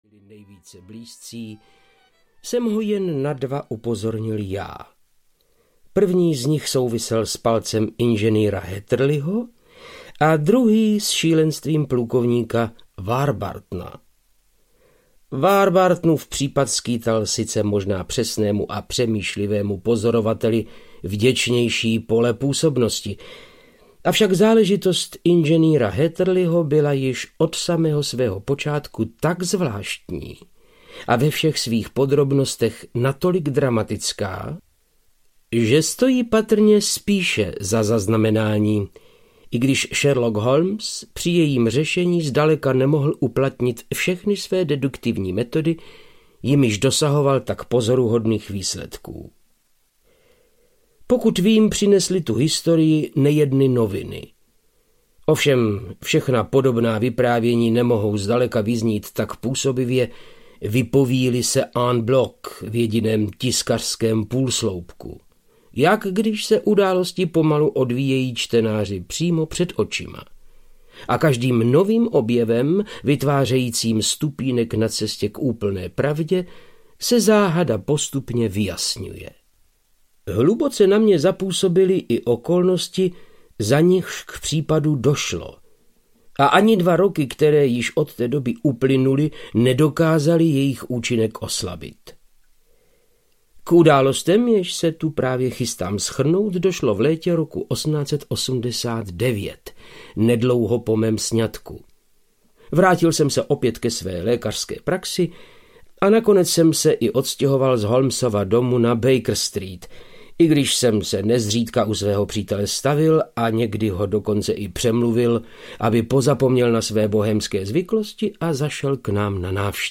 Inženýrův palec audiokniha
Ukázka z knihy
• InterpretVáclav Knop